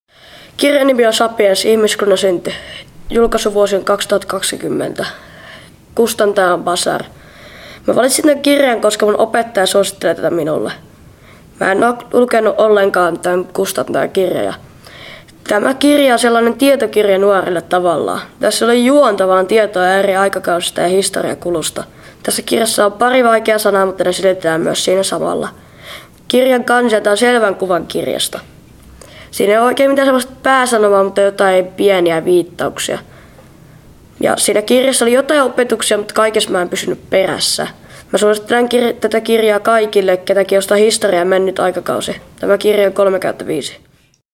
Kirjaesittely: Yuval Noah Harari – Sapiens | radiofiskars